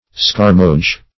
Meaning of scarmoge. scarmoge synonyms, pronunciation, spelling and more from Free Dictionary.
scarmoge.mp3